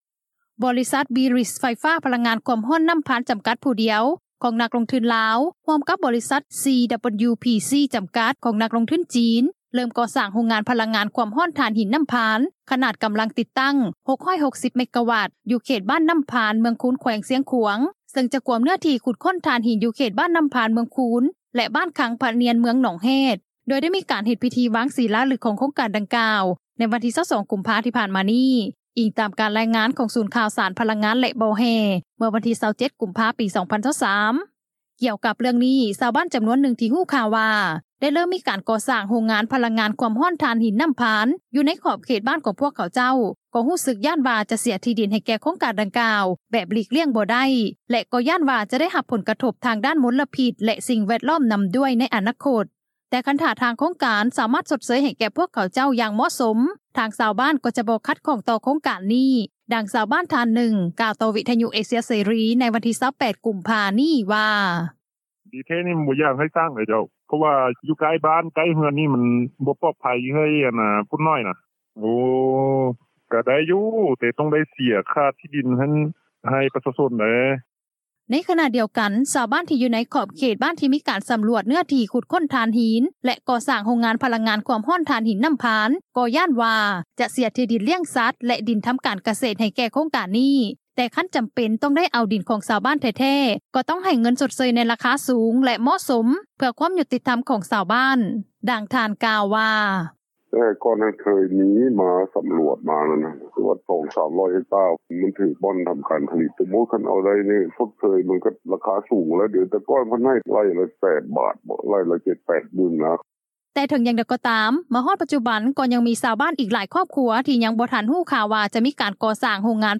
ດັ່ງຊາວບ້ານທ່ານນຶ່ງ ກ່າວຕໍ່ວິທຍຸເອເຊັຽເສຣີ ໃນວັນທີ 28 ກຸມພາ ນີ້ວ່າ:
ດັ່ງຊາວບ້ານນາງນຶ່ງ ກ່າວວ່າ: